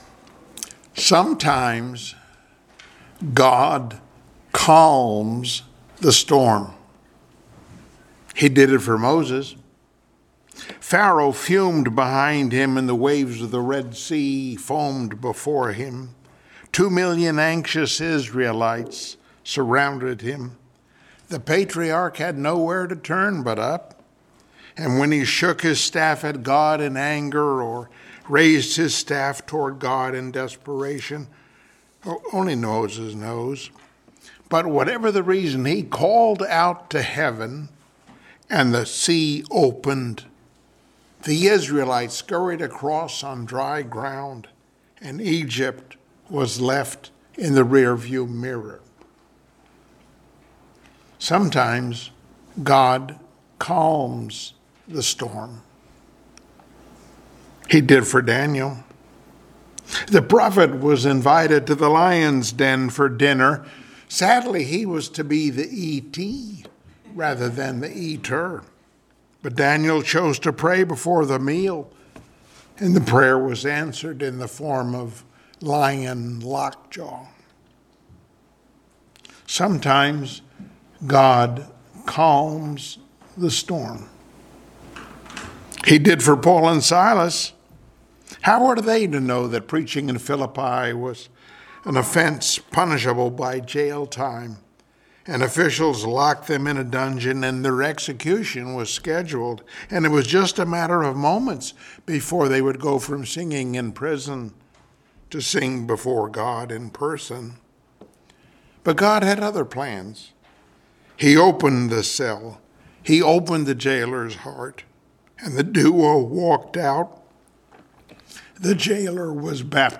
Passage: Philippians 4:4-7 Service Type: Sunday Morning Worship Topics